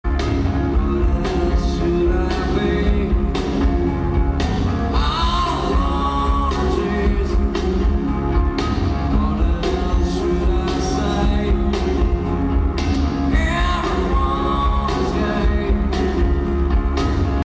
Audio : 8/10 Un bon son provemant de la video.